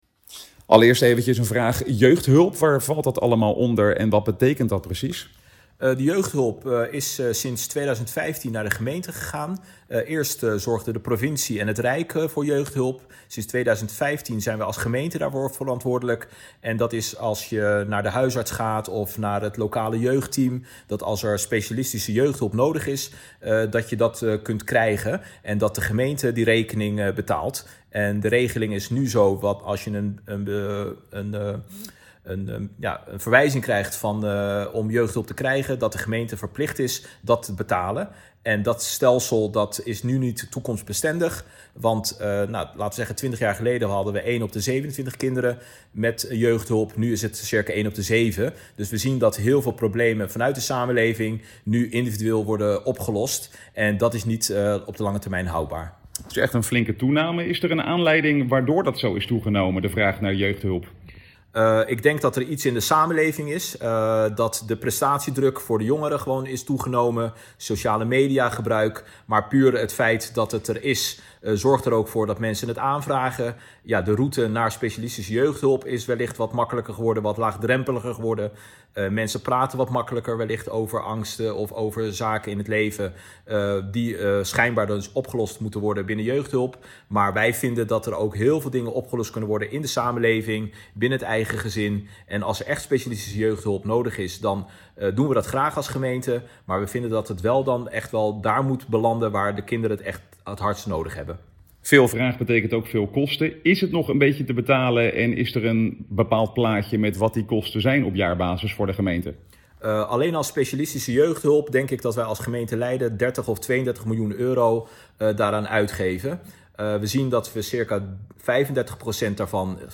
Wethouder Abdelhaq Jermoumi vertelt over het belang van de nieuwe Verordening Jeugdhulp: